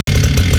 sfx_chainsaw_idle_2.wav